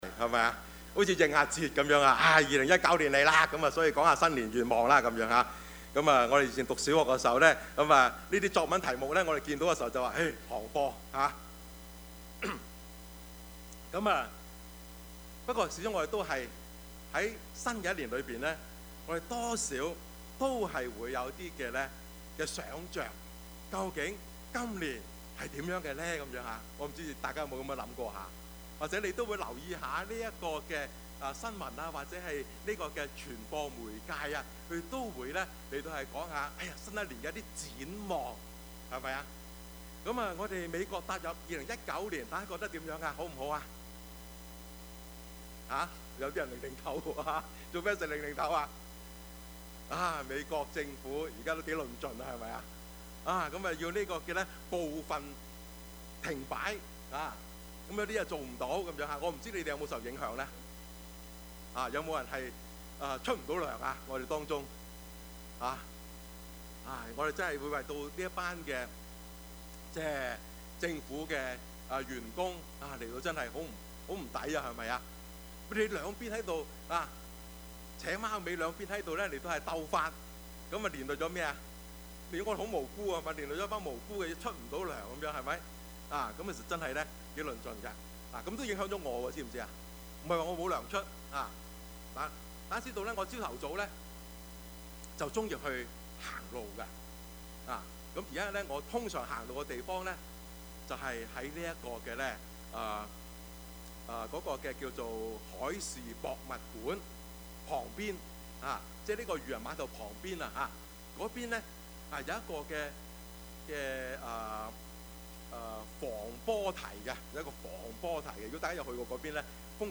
Service Type: 主日崇拜
Topics: 主日證道 « 最後晚餐 網中人 »